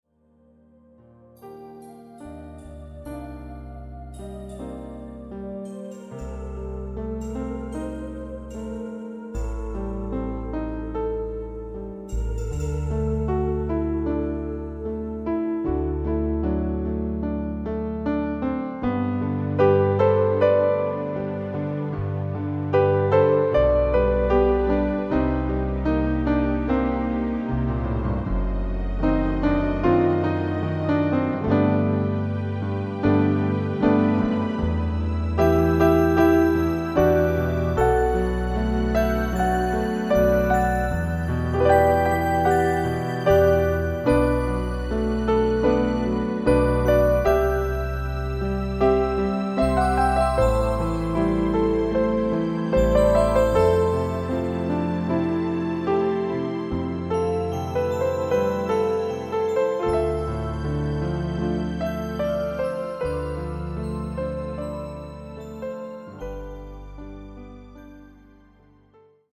relaxing soothing music